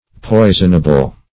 Search Result for " poisonable" : The Collaborative International Dictionary of English v.0.48: Poisonable \Poi"son*a*ble\, a. 1.